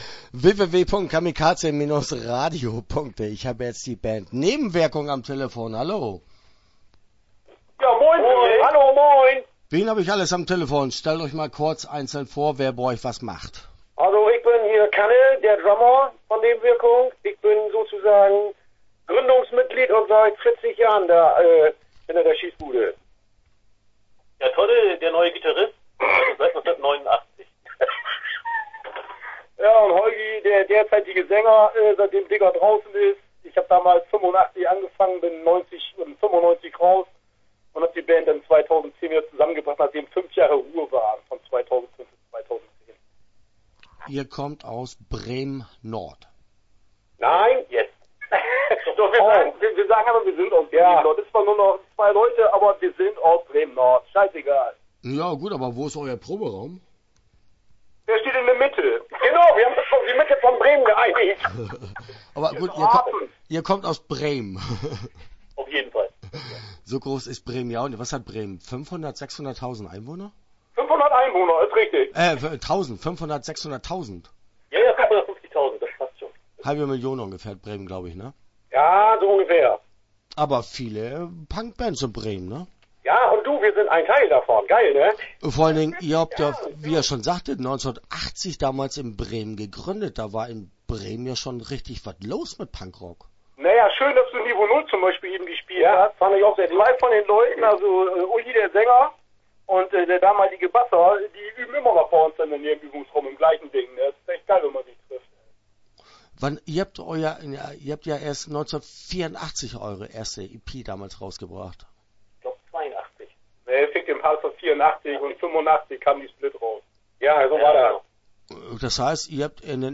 Nebenwirkung - Interview Teil 1 (10:26)